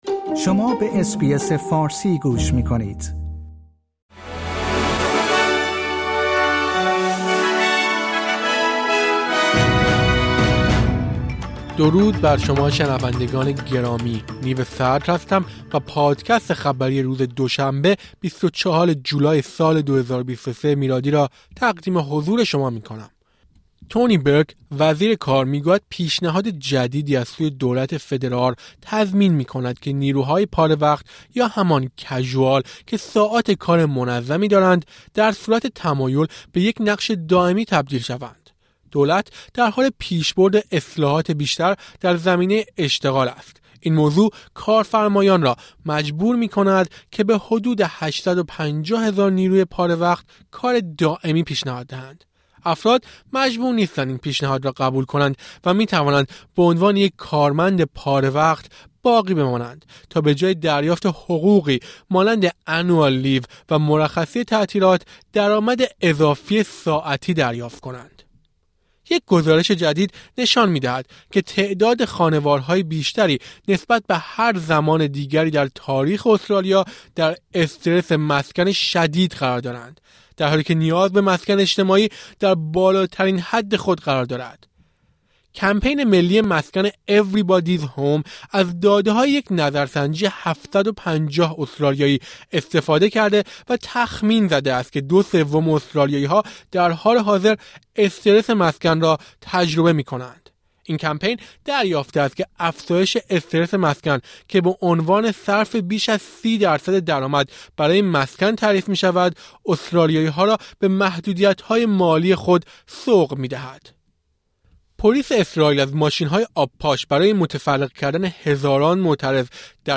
در این پادکست خلاصه‌ای از مهمترین اخبار استرالیا در روز دوشنبه ۲۴ جولای، ۲۰۲۳ ارائه شده است.